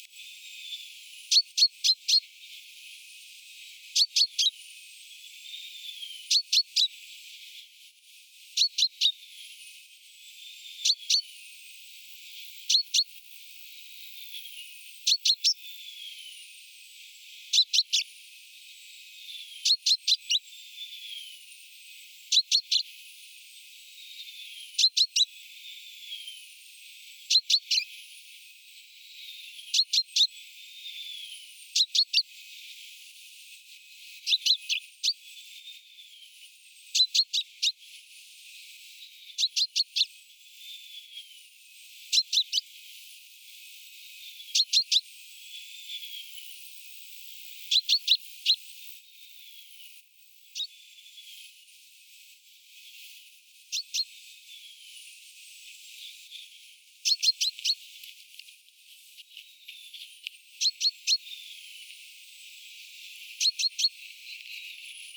ilmeisen nuoren peipon huomioääntelyä
noin kahden metrin päästä äänitettynä
ilm_nuoren_peipon_huomioaantelya_noin_2_metrin_paasta_aanitettyna.mp3